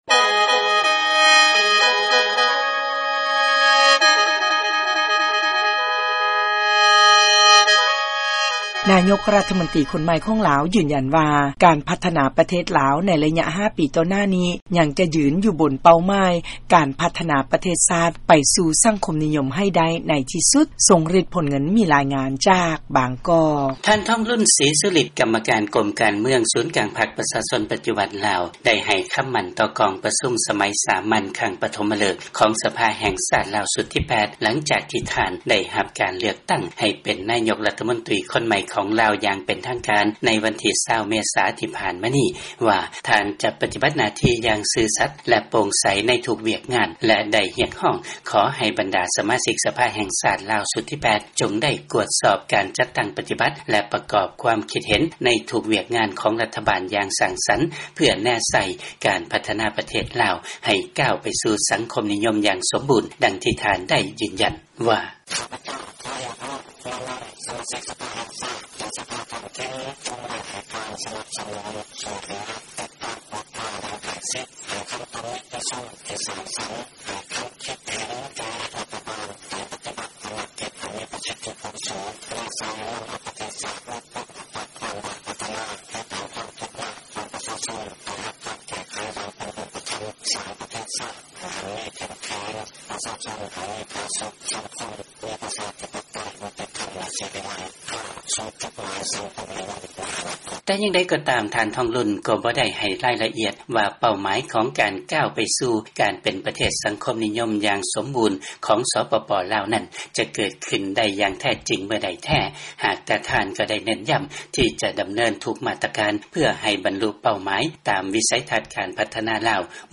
ນາຍົກລັດຖະມົນຕີ ລາວ ທ່ານ ທອງລຸນ ສີສຸລິດ ກ່າວຄຳປາໄສໃນລະຫວ່າງກອງປະຊຸມສະພາແຫ່ງຊາດ.